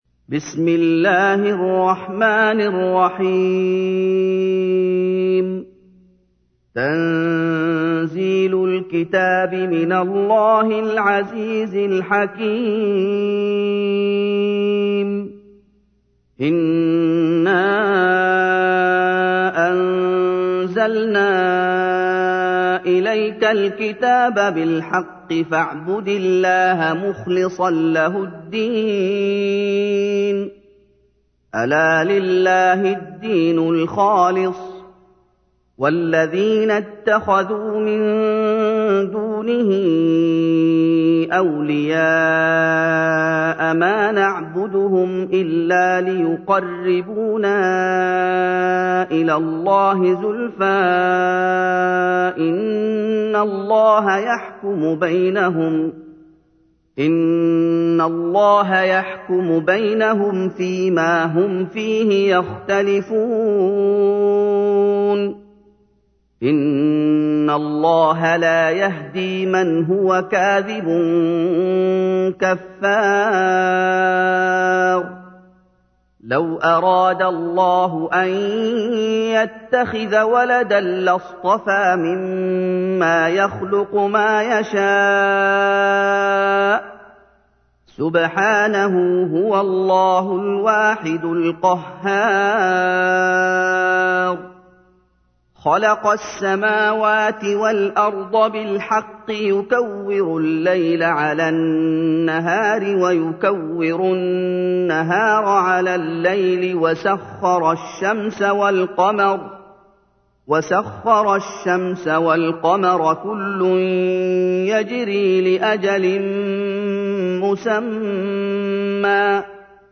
تحميل : 39. سورة الزمر / القارئ محمد أيوب / القرآن الكريم / موقع يا حسين